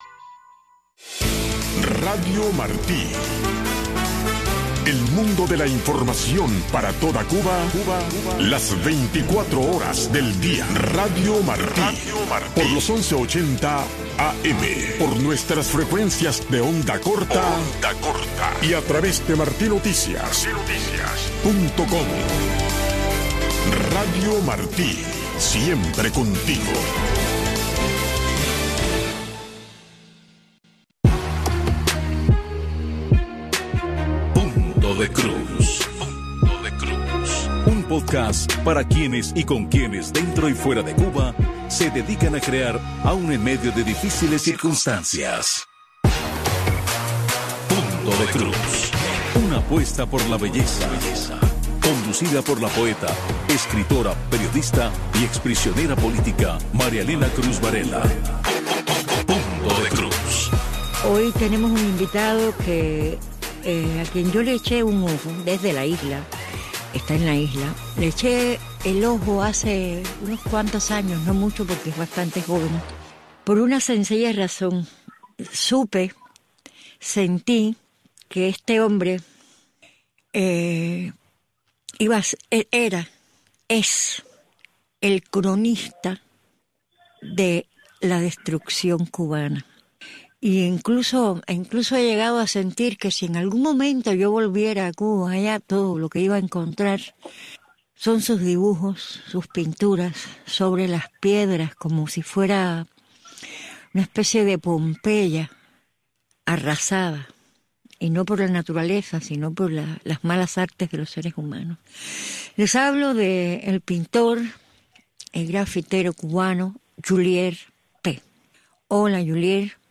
Las voces que testimonian la vida del cubano de a pie.